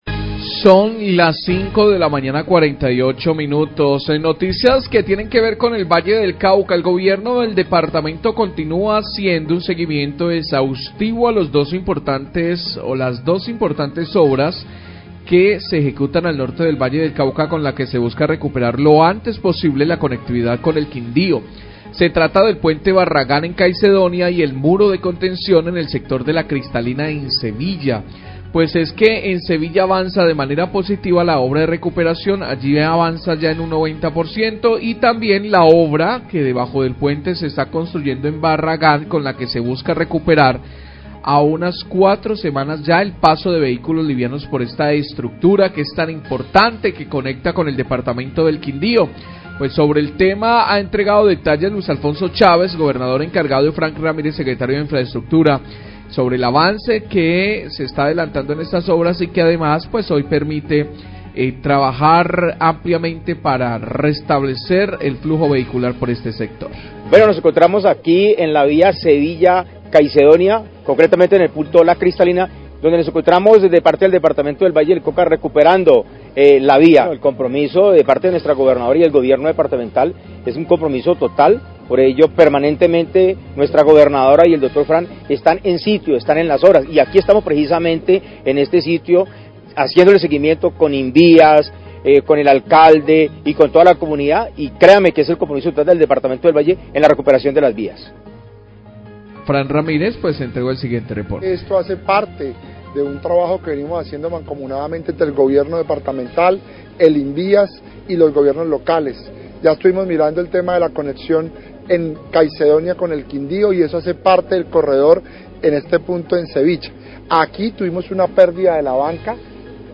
Radio
El Gobernador(E) del Valle, Luis Alfonso Chávez, y el Secretario de Infaestructura departamental, Frank Ramírez, hablan de los trabajos adelantados en el sector de La Cristalina (Sevilla) y las obras de reforzamiento del Puente de Barragán, lo que permitirá la rehabilitación de la movilidad entre el Departamento del Valle y del Quindio.